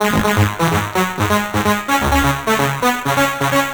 Scuzzy Brass Ab 128.wav